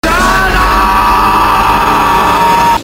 Tags: sports radio